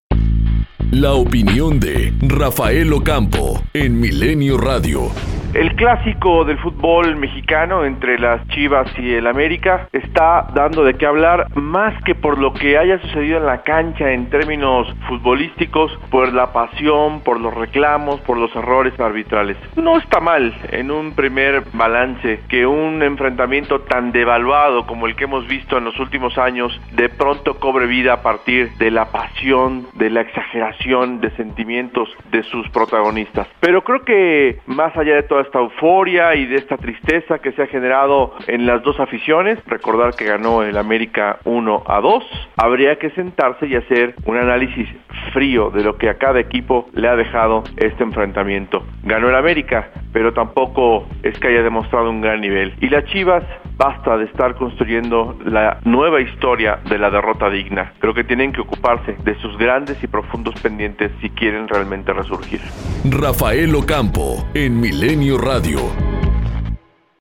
COMENTARIO EDITORIAL 140316